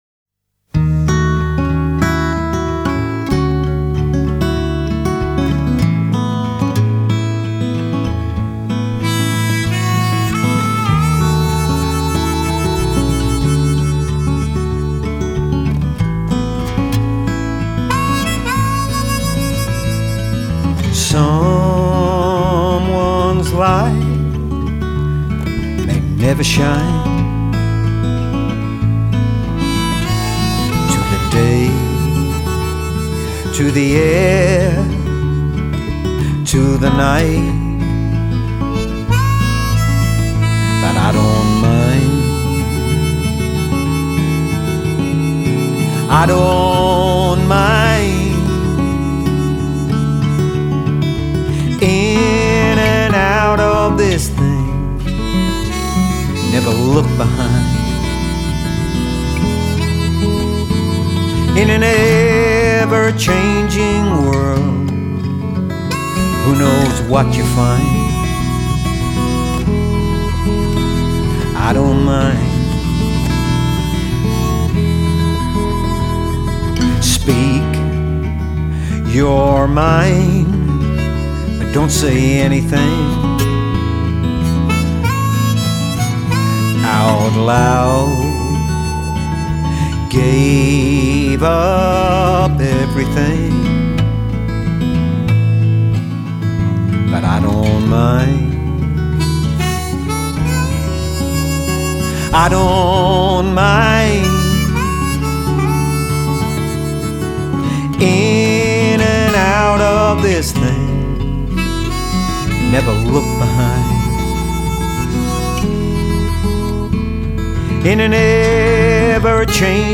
sweet harmonica tones
Australian country music